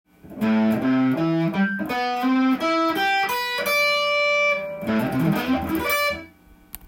tab譜のフレーズはAmキーで使用できます。
③のフレーズを更に発展させてスィープピッキングの幅が
広くなっています。これだけスィープするともの凄く上手く聞こえます。